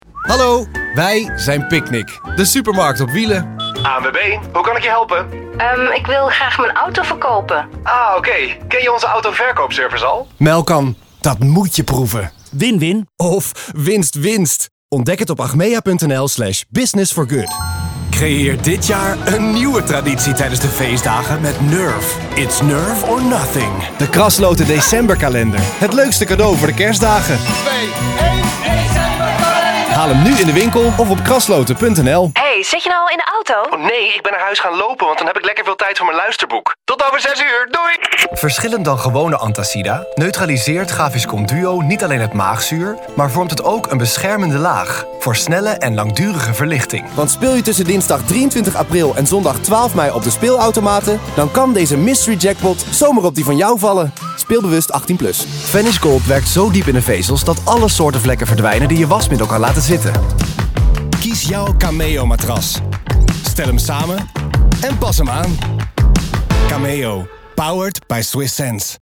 Comercial, Versátil, Seguro, Amable, Cálida
Comercial
Think of a fresh, mature voice with that typical millennial sound: clear, accessible and fresh.